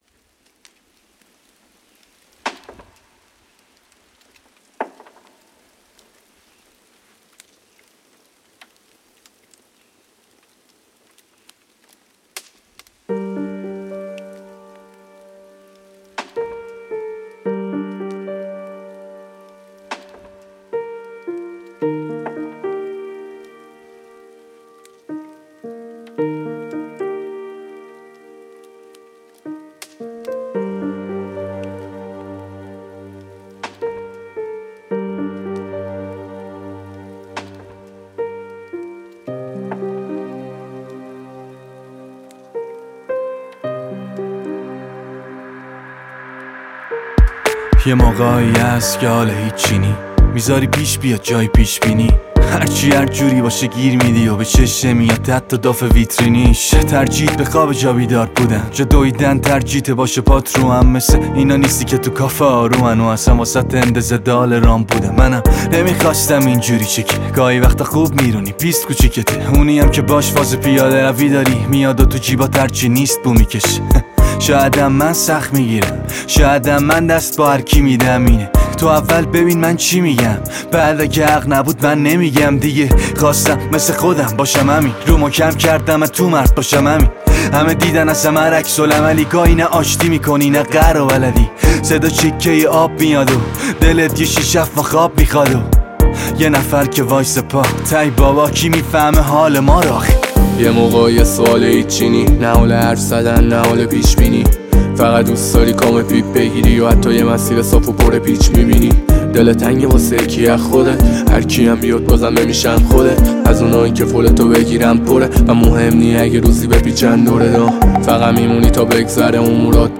رپ